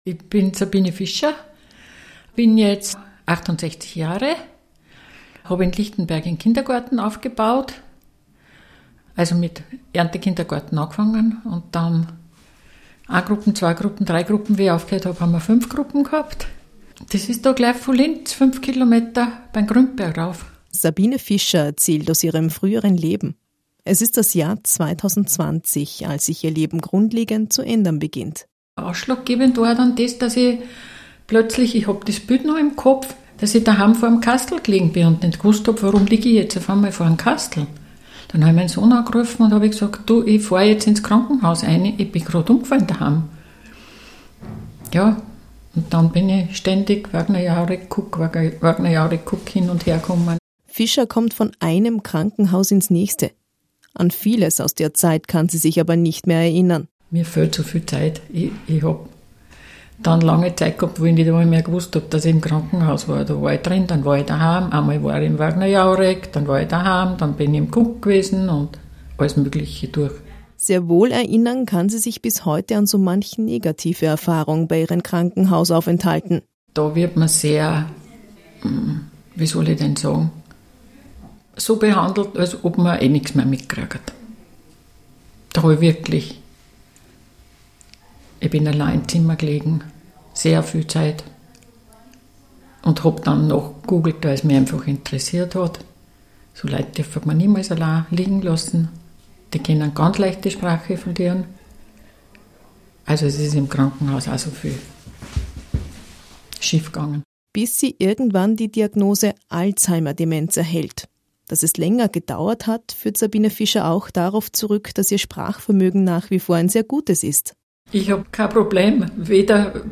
Eine Betroffene erzählt.